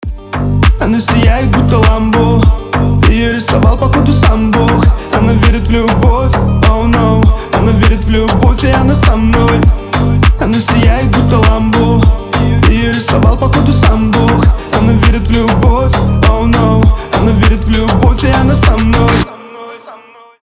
мужской голос
басы